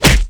空手击中zth070510.wav
通用动作/01人物/03武术动作类/空手击中zth070510.wav